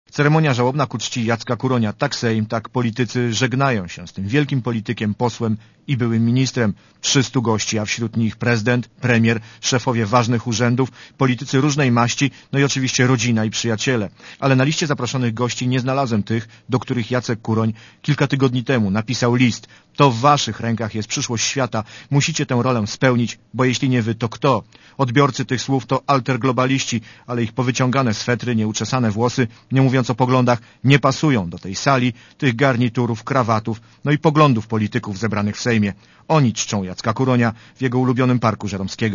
Relacja reportera Radia ZET
Uroczystość odbyła się w Sali Kolumnowej Sejmu.